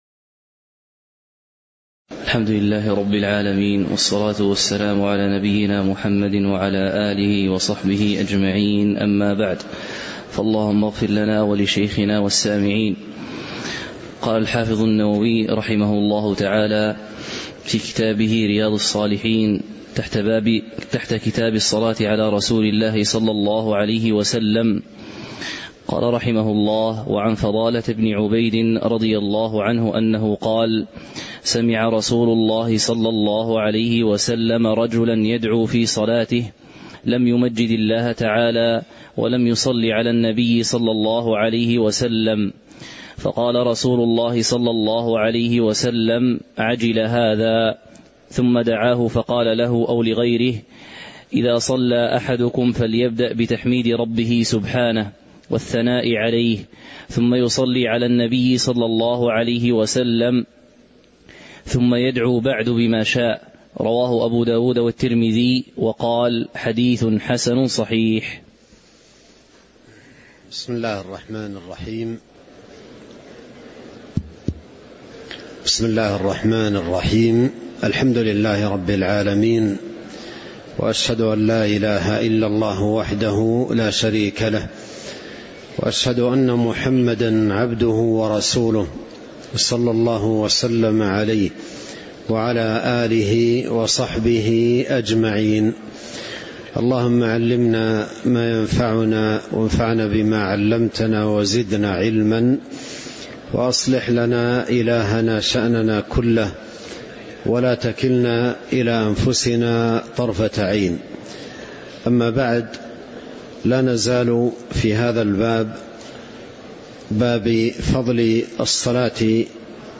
تاريخ النشر ٢٨ رجب ١٤٤٥ هـ المكان: المسجد النبوي الشيخ: فضيلة الشيخ عبد الرزاق بن عبد المحسن البدر فضيلة الشيخ عبد الرزاق بن عبد المحسن البدر باب فضل الصلاة على رسول الله (02)